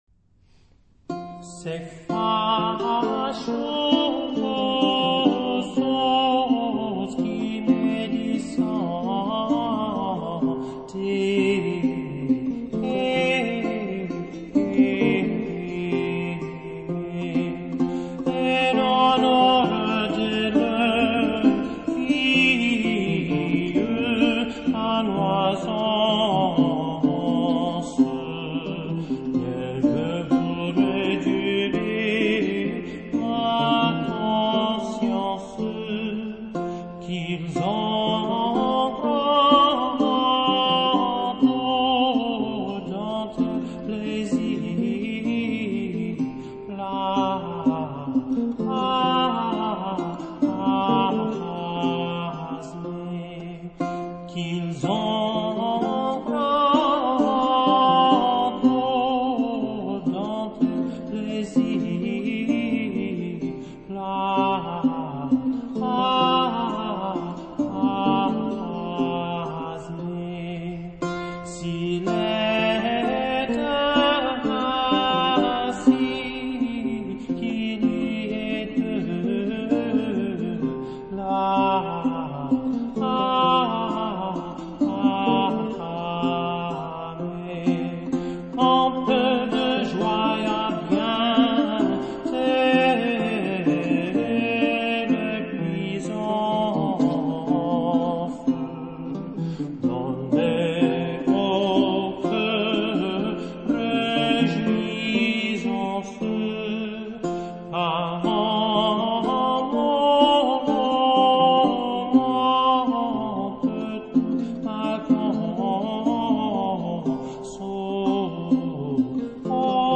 chamber music
classical
vihuela, renaissance and baroque lute